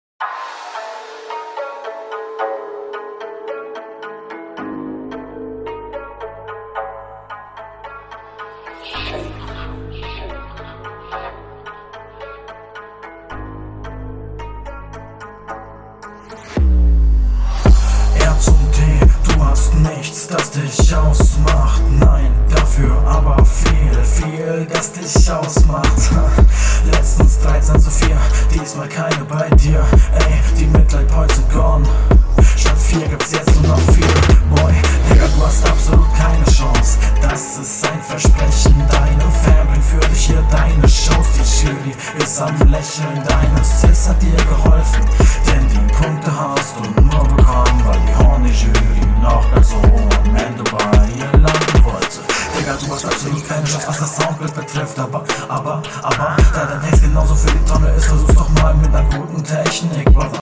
Sehr schöner Beatpick schonmal aber der Stimmeinsatz ist bisschen zu lahm. Da solltest du bisschen …
Hallo ich finde du kommst zu leise von deiner Abmische bist sehr gut aufm dem …